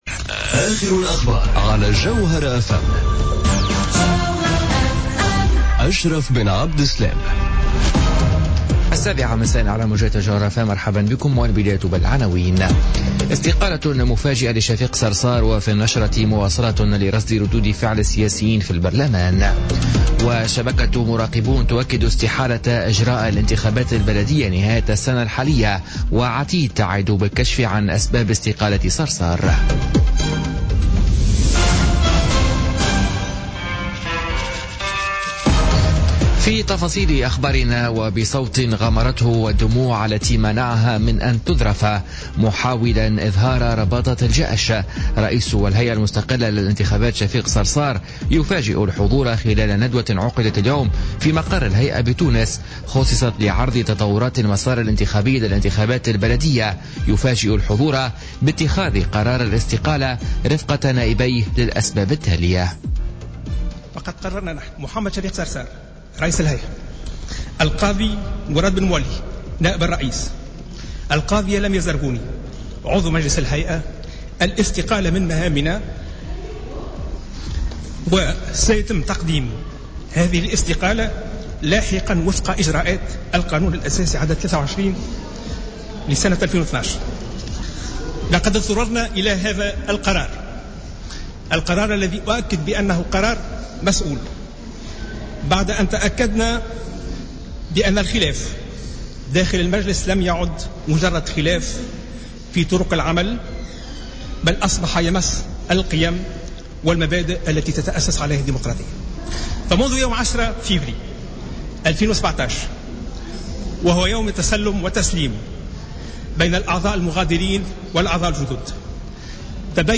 نشرة أخبار السابعة مساء ليوم الثلاثاء 9 ماي 2017